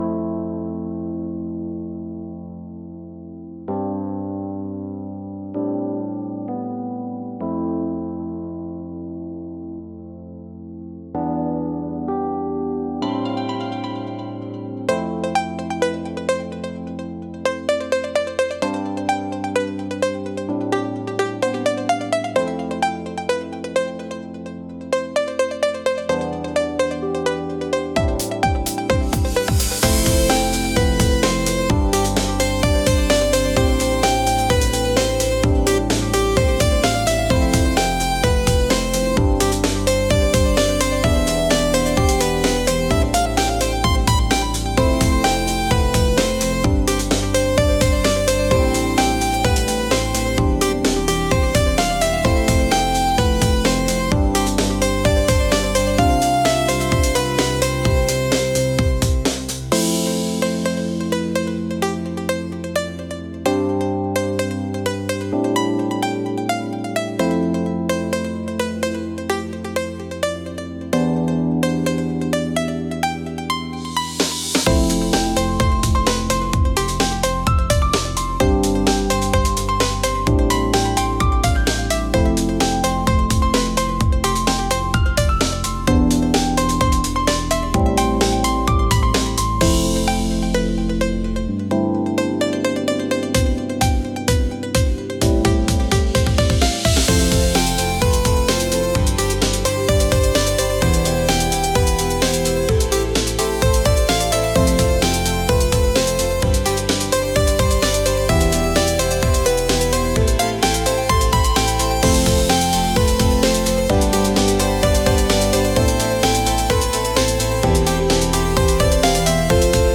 何か繁華街かアパレルショップに似合うかも知れない曲が仕上がりました。